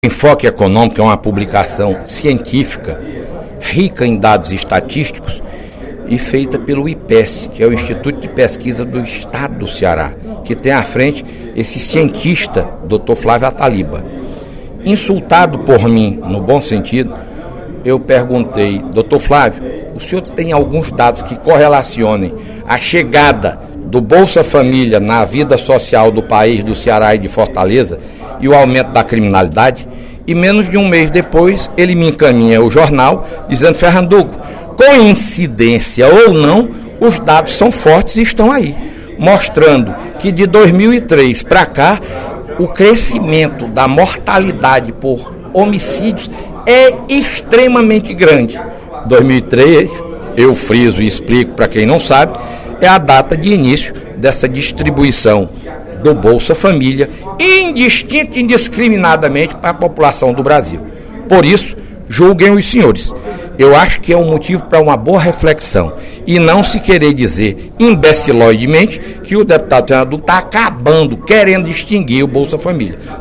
Primeiro orador do primeiro expediente da sessão plenária desta quarta-feira (03/04), o deputado Fernando Hugo (PSDB) relacionou a chegada do Bolsa Família com o aumento da violência no Estado.